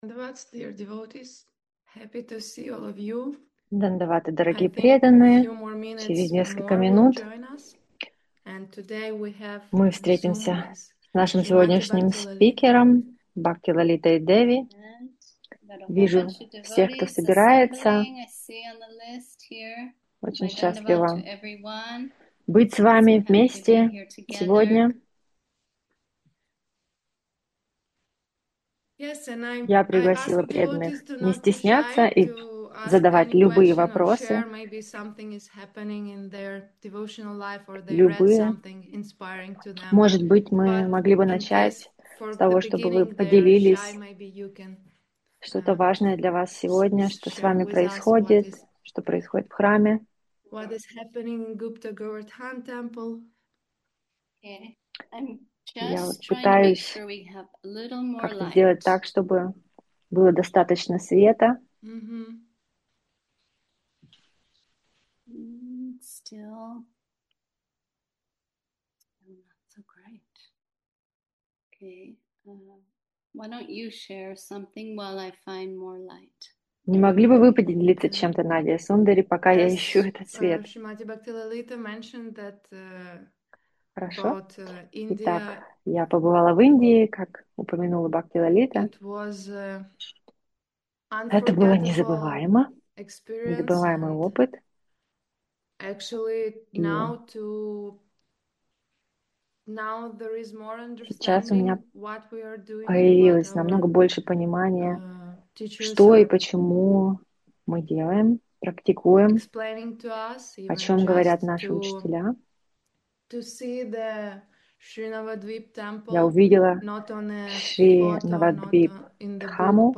ZOOM ответы на вопросы | «Сокровища преданных».